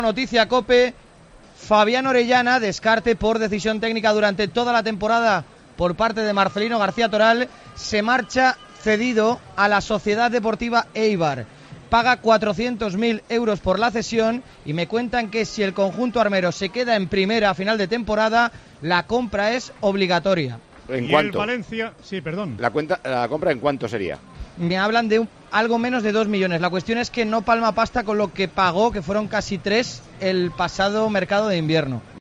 Noticia Deportes COPE Valencia